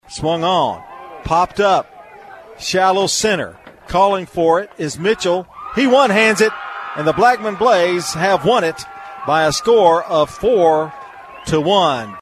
with the call on NewsRadio WGNS as the Blackman Blaze defeated Walker Valley 4-to-1 to advance to the state tournament for the second time in school history.